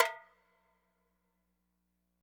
MB Perc (11).wav